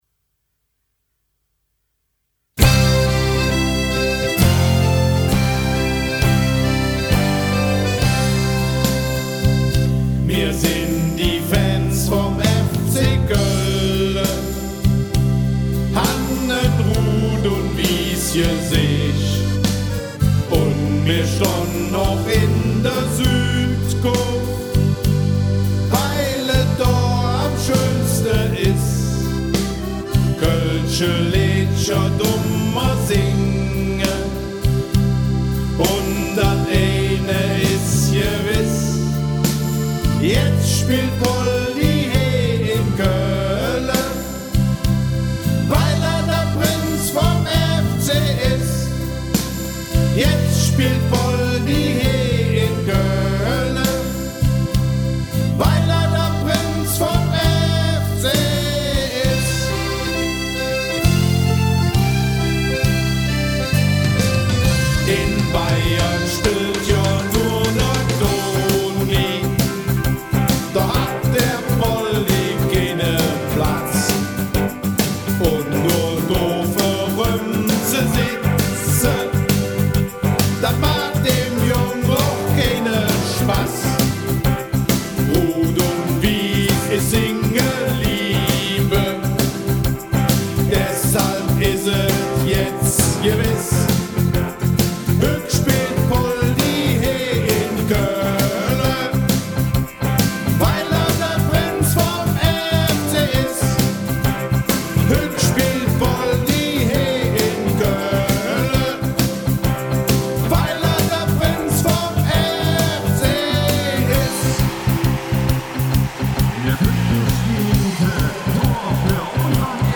ganz neue rockige Super-Karnevals-Version